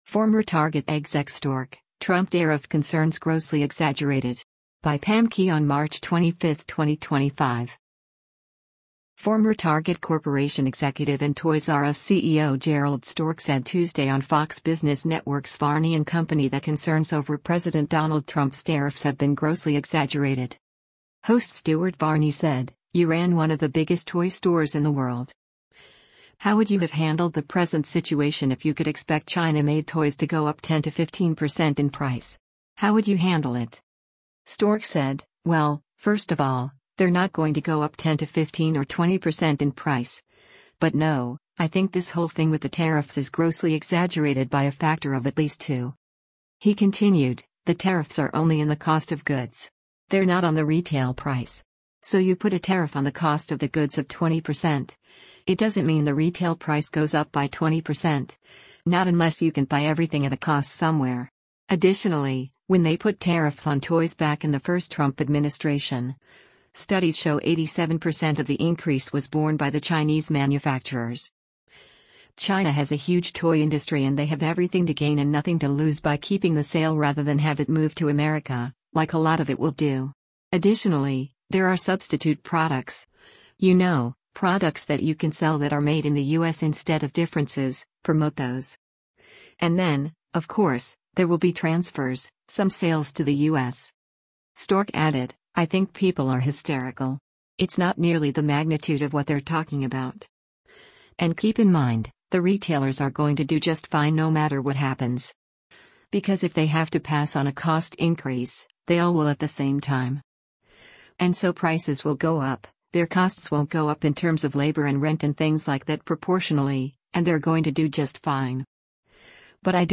Host Stuart Varney said, “You ran one of the biggest toy stores in the world. How would you have handled the present situation if you could expect China made toys to go up 10 to 15% in price? How would you handle it?”